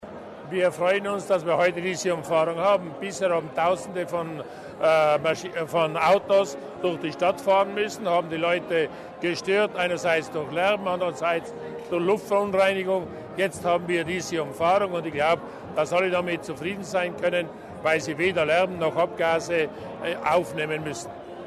Landesrat Mussner zur Bedeutung des Projekts